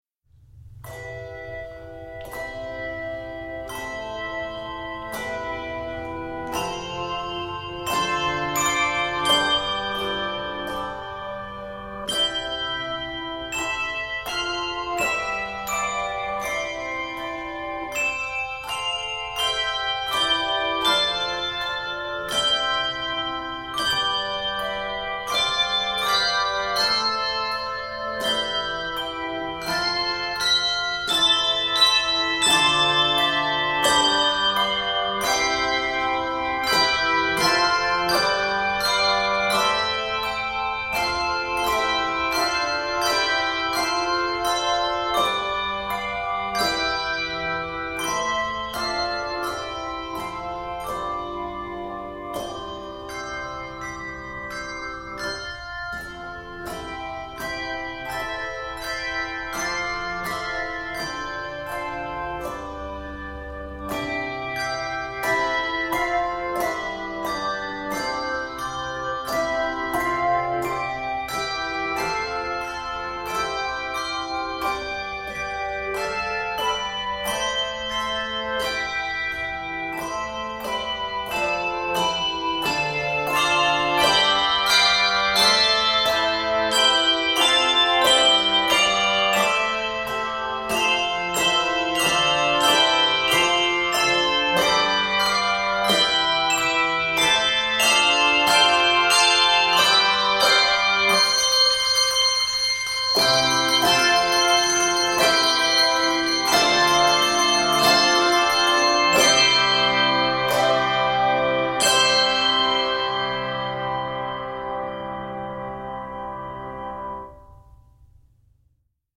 handbells
It is scored in C Major.